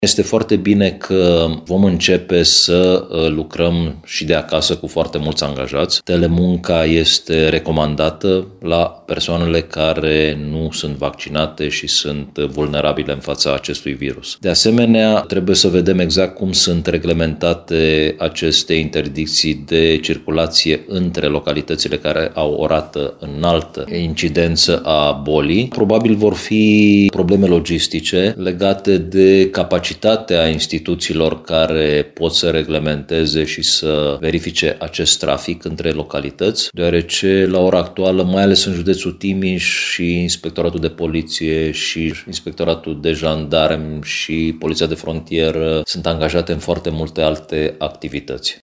Autoritățile analizează, totodată, și cum anume vor putea pune în aplicare restricțiile de circulație între localitățile cu incidență mare a bolii, mai spune Ovidiu Drăgănescu: